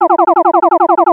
Sons et bruitages de jeux vidéos